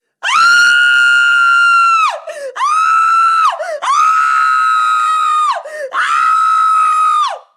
Mujer gritando de terror
gritar
Sonidos: Acciones humanas
Sonidos: Voz humana